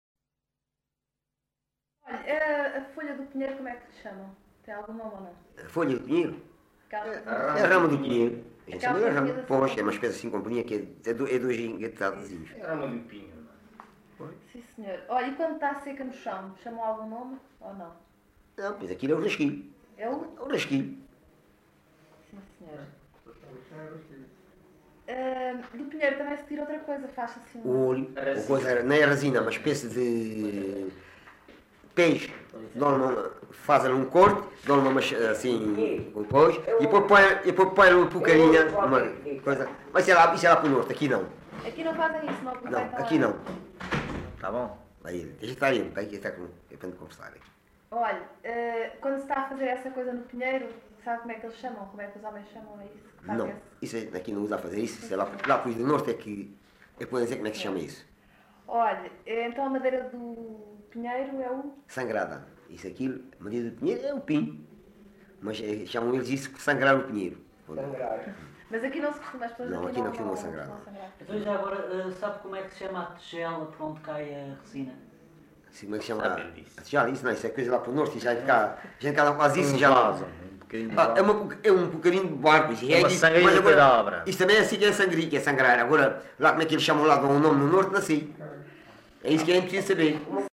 LocalidadeAlte (Loulé, Faro)